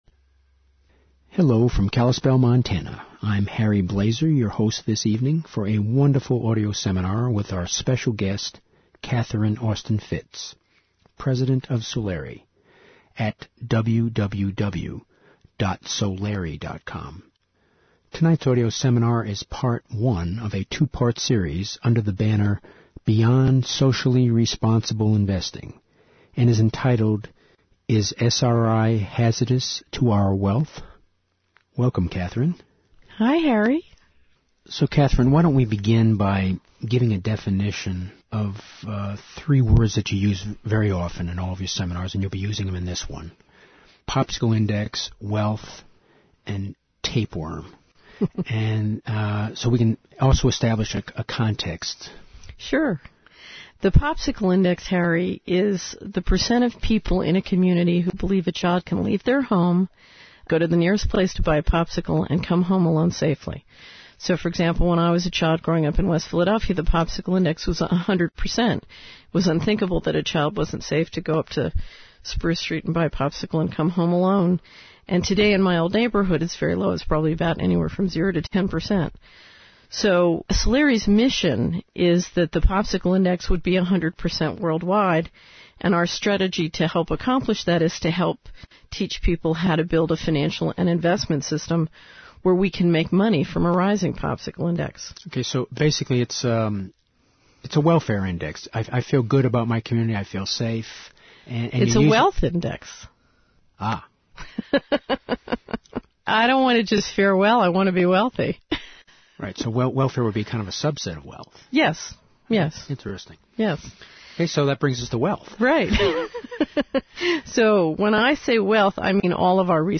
In this seminar, successful Wall Street investment banker and entrepreneur Catherine Austin Fitts reveals: